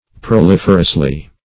[1913 Webster] -- Pro*lif"er*ous*ly, adv.